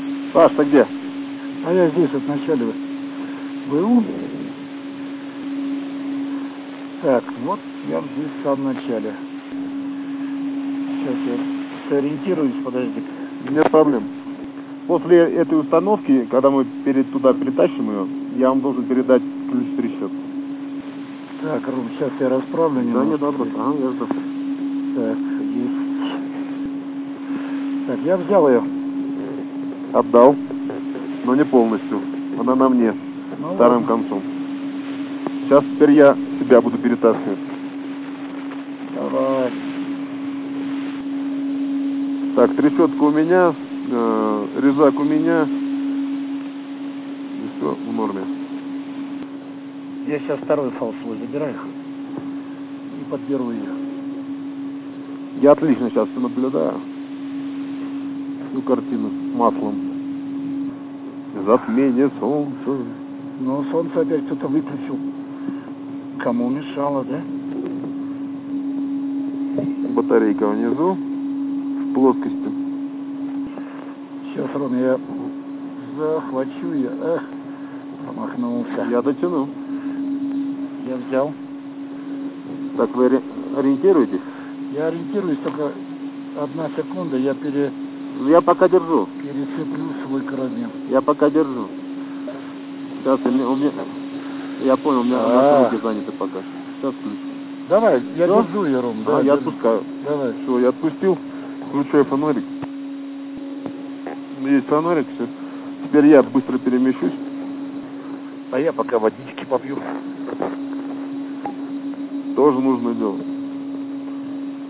Выход в открытый космос